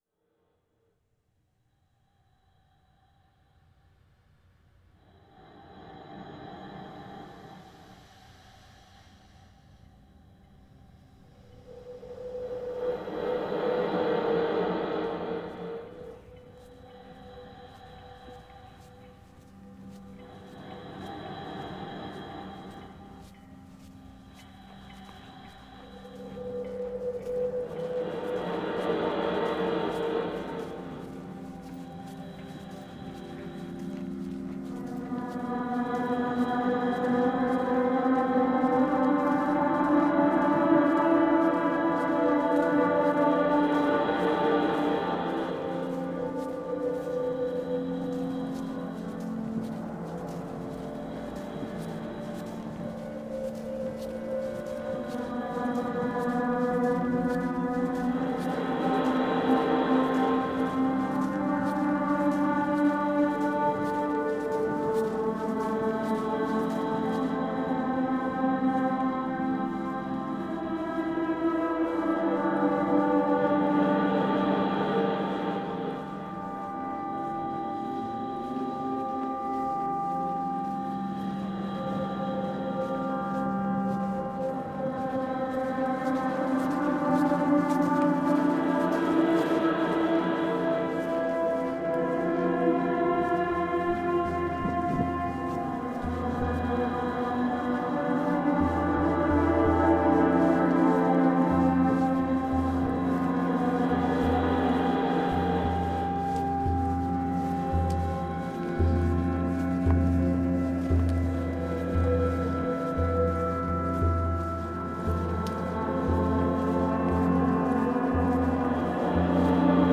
Art, podcast, Sound Exploration
Another lovely sonic story time collaboration!